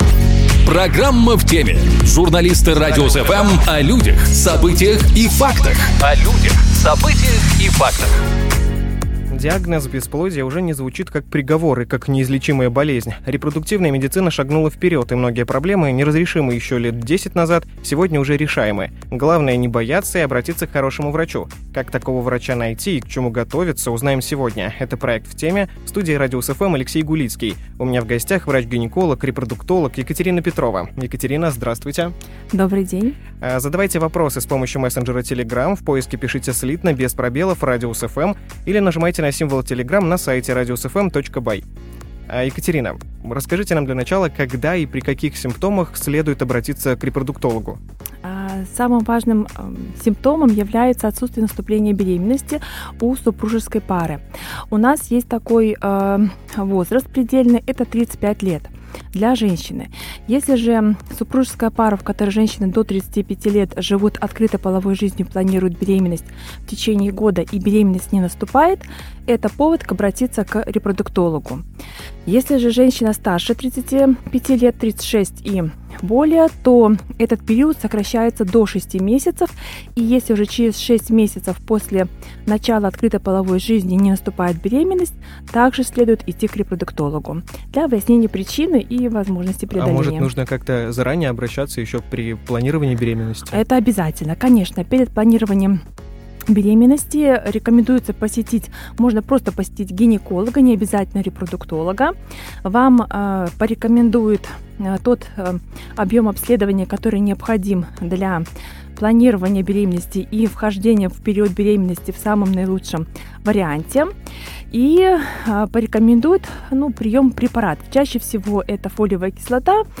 Как такого врача найти и к чему готовиться, узнаем сегодня "В теме" В гостях у "Радиус FM"